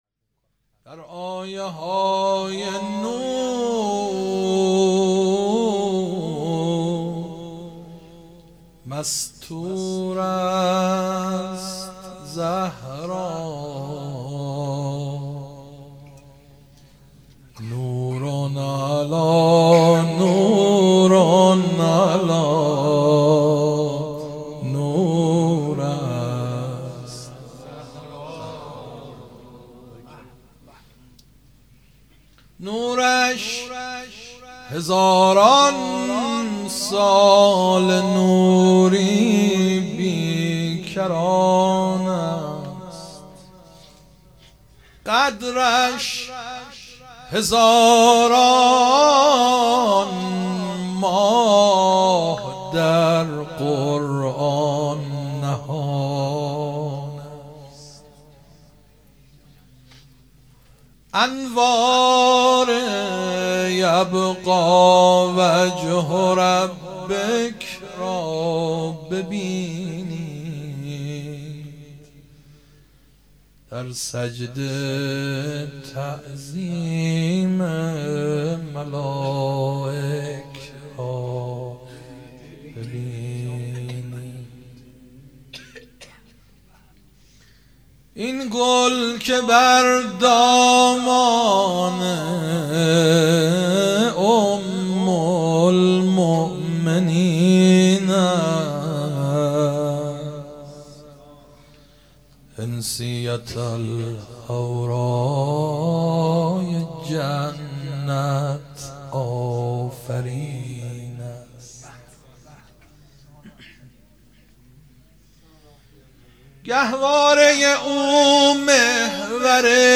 مدح ۲ | بر آیه های نور مستور است زهرا مداح
هیئت مکتب الزهرا(س)دارالعباده یزد